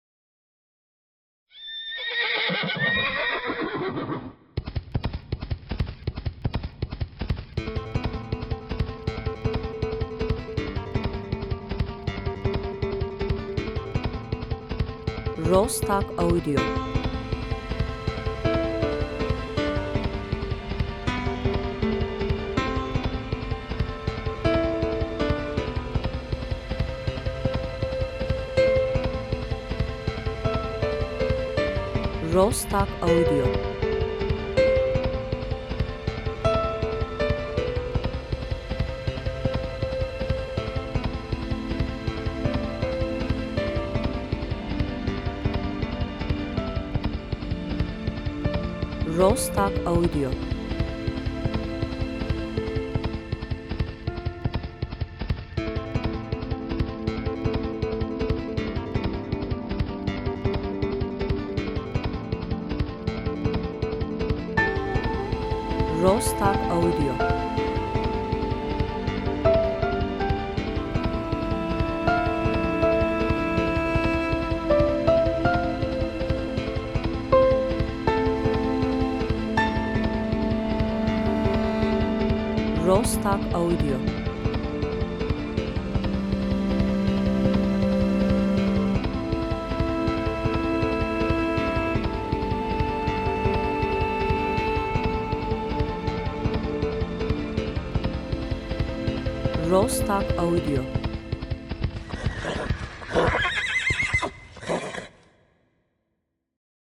enstrümantal müzik
epik epic